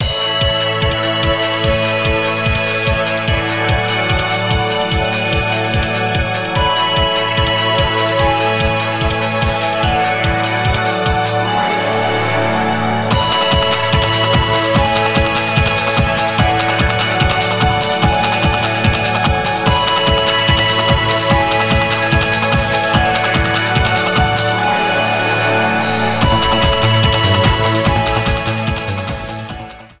Cut at 30s, fadeout of 3s at the end.